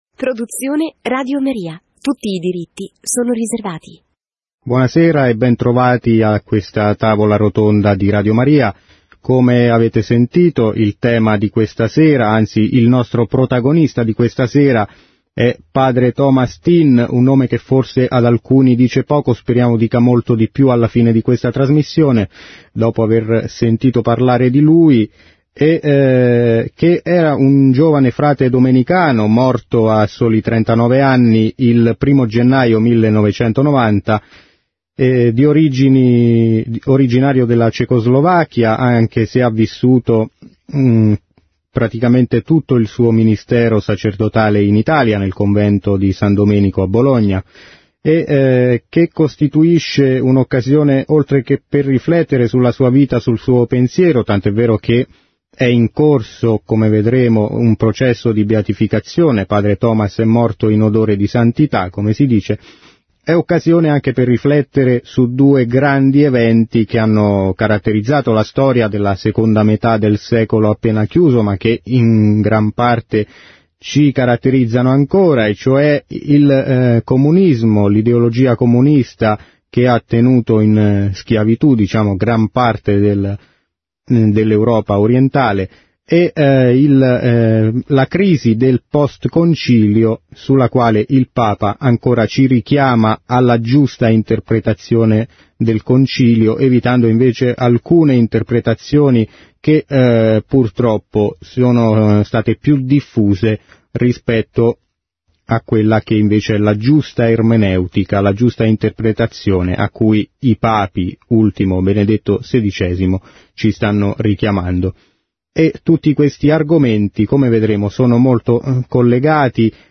Domenica 30 dicembre 2012 , in vista del 23� anniversario del dies natalis, Radio Maria ha trasmesso una tavola rotonda dal titolo �Padre Tom� T�n: apostolo della Verit�, vittima per la libert��.